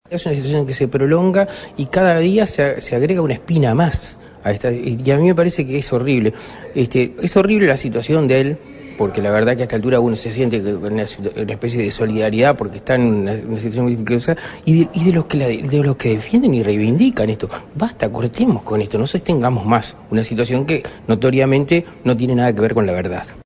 Desde el Partido Independiente, opinó el diputado Daniel Radío: